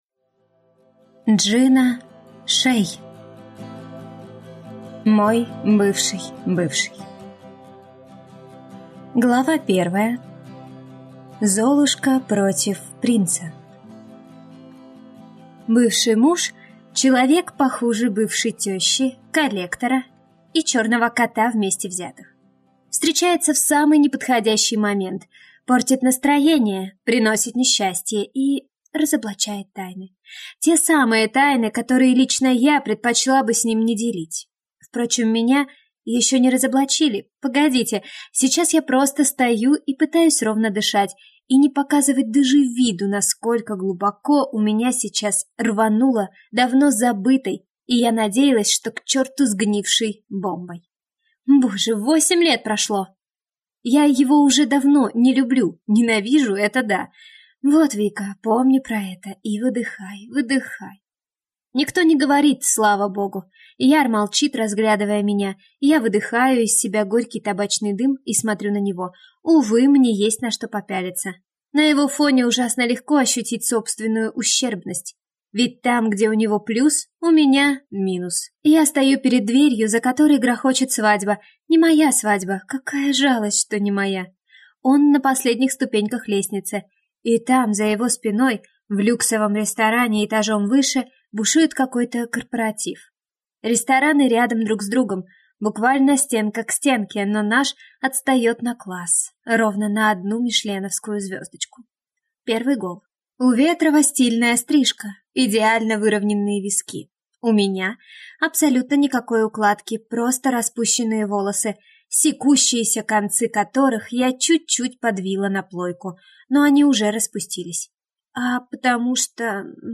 Аудиокнига Мой бывший бывший. Книга 1 | Библиотека аудиокниг